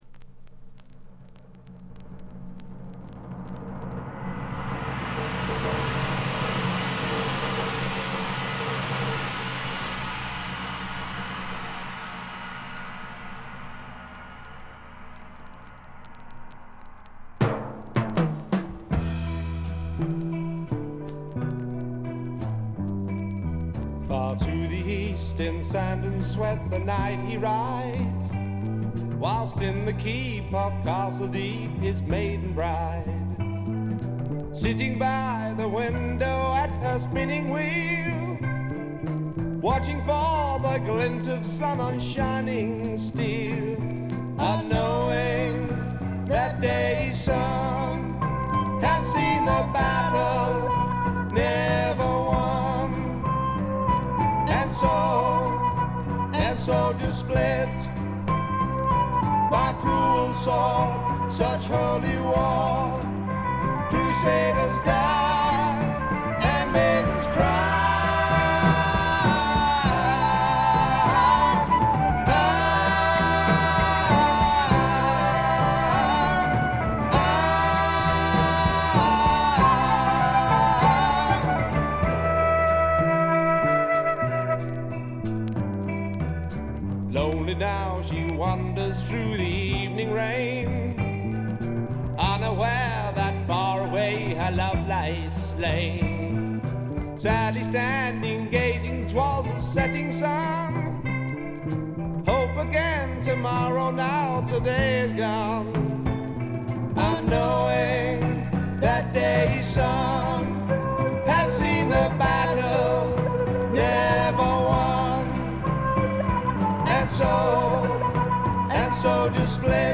サックスや木管を大胆に導入したアコースティック主体の演奏でジャズ色のある独特のフォーク・ロックを奏でる名作です。
試聴曲は現品からの取り込み音源です。